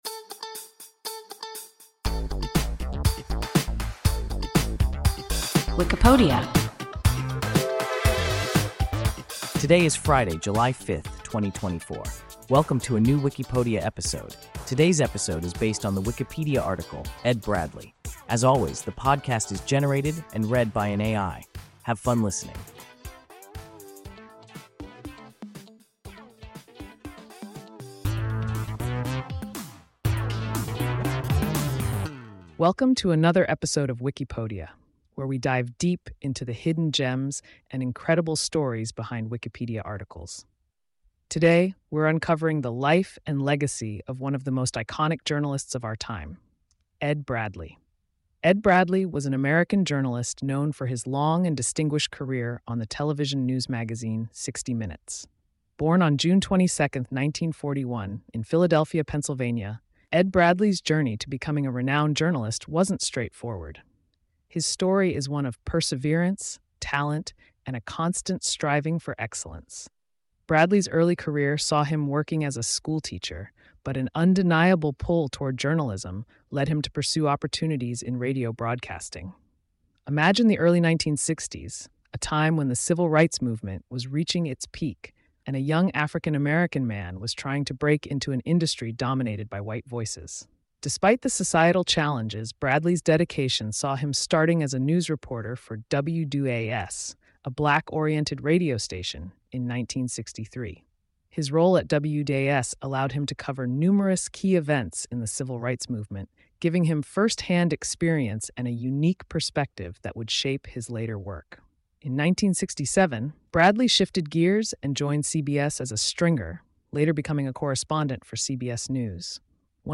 Ed Bradley – WIKIPODIA – ein KI Podcast